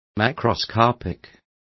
Complete with pronunciation of the translation of macroscopic.